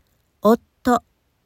If you want to express the double sound, you’re also going to make use of sokuon: っ or ッ(the small vowel of つ and ツ)By writing it before a consonant, it doubles the consonant.